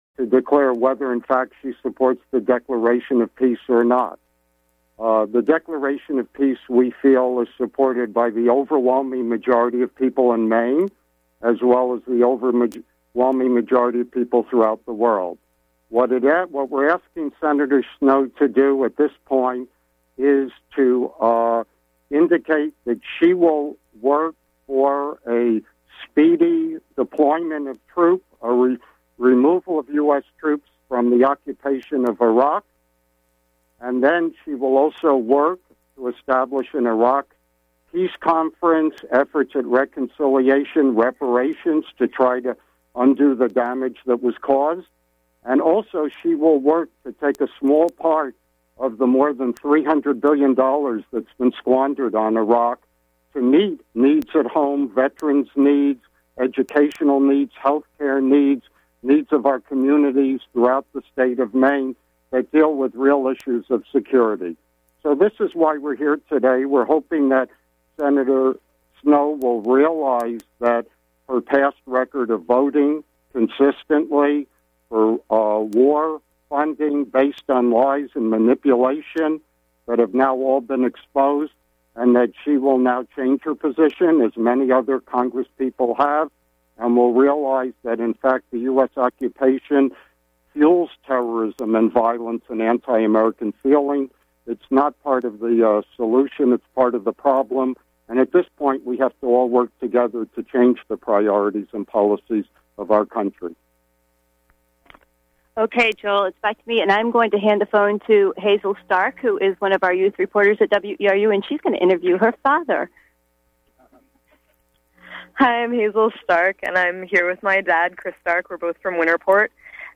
Note: Beginning/introduction clipped—this recording joins the show in progress